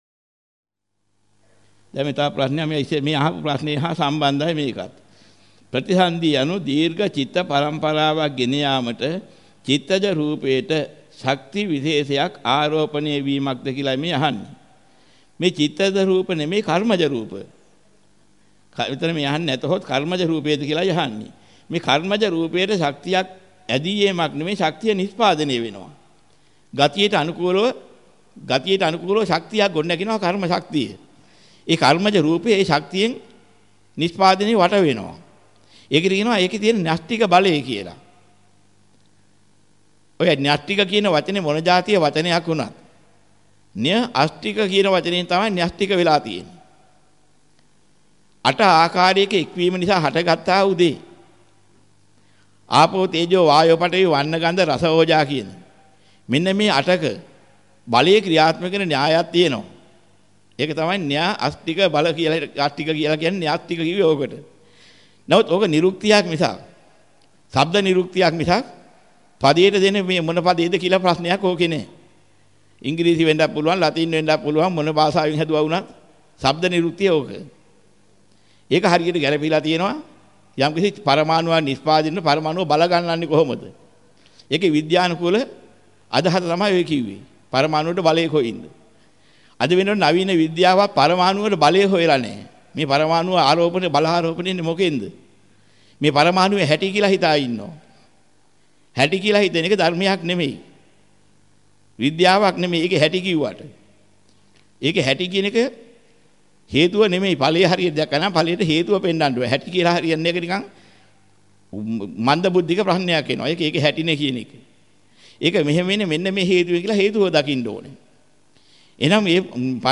වෙනත් බ්‍රව්සරයක් භාවිතා කරන්නැයි යෝජනා කර සිටිමු 22:56 10 fast_rewind 10 fast_forward share බෙදාගන්න මෙම දේශනය පසුව සවන් දීමට අවැසි නම් මෙතැනින් බාගත කරන්න  (14 MB)